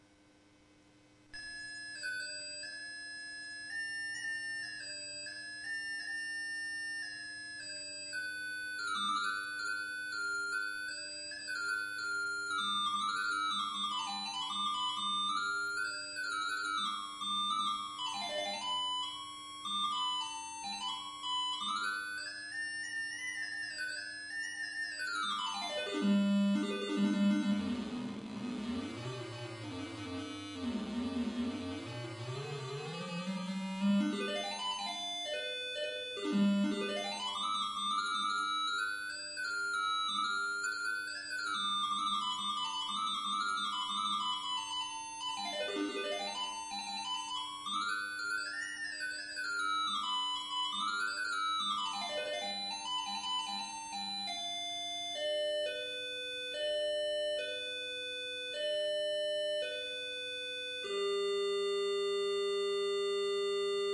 唱歌的电子管
也许这种会唱歌的电子管更适合于人的耳朵。这是一个古老的KL66高保真电子管。
声道立体声